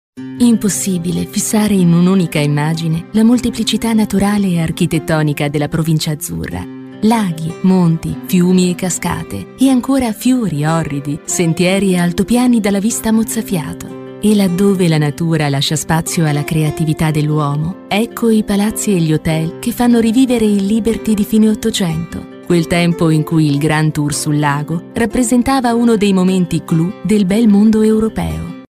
Her articulate, engaging delivery suits commercials, narration, e-learning, and character work – perfect for brands seeking a professional Italian voice actor.
Documentaries
Mic Neumann TLM103